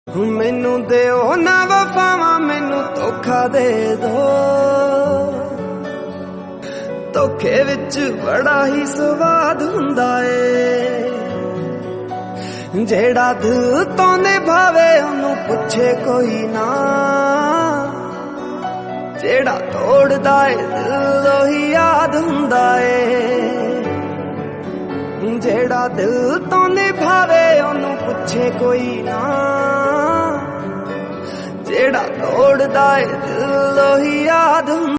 Sad Punjabi Song Ringtone